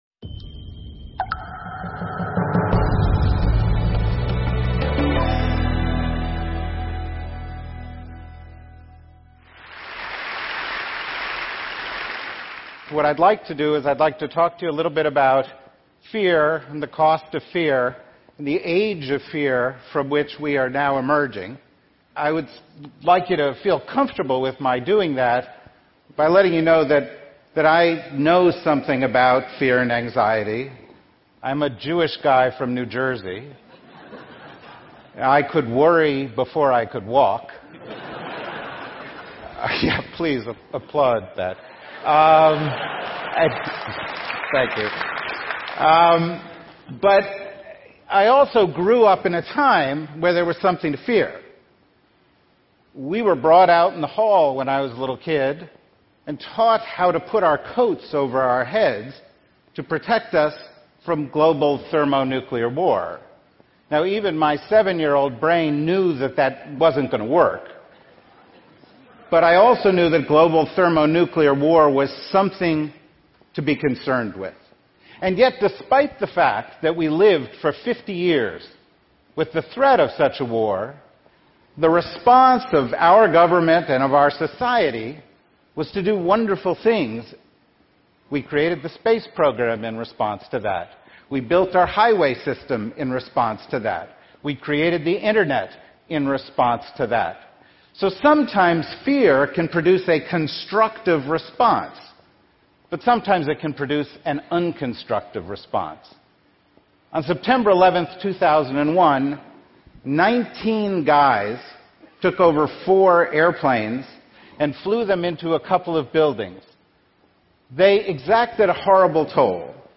Full text of David Rothkopf, a foreign policy strategist on How Fear Drives American Politics at TED conference.